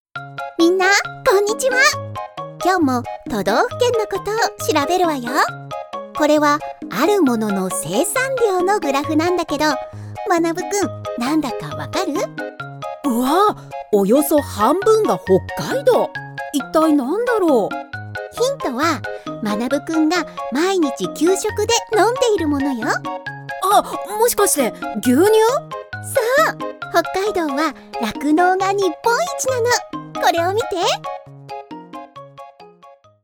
Animation
Sa voix douce, claire et attachante est très polyvalente, ce qui en fait le choix incontournable de nombreuses grandes entreprises et organismes gouvernementaux.